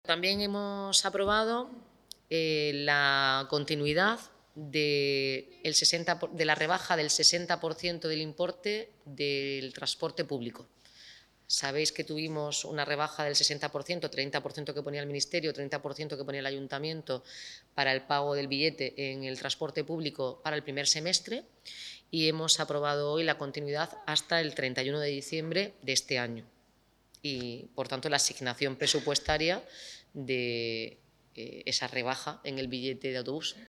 Enlace a Declaraciones de la alcaldesa por la prórroga hasta diciembre de la bonificación del precio del bus urbano en Cartagena